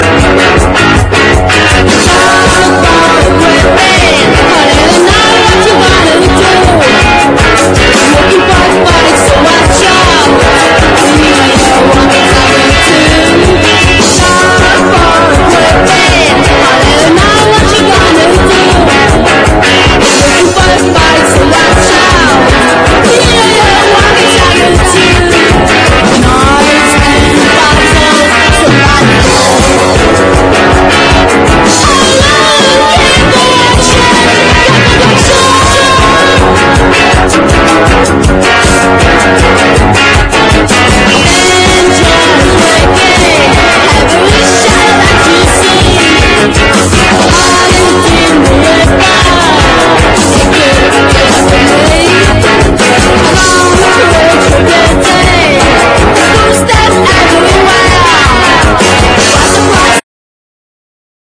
POST PUNK DISCO / BALEARIC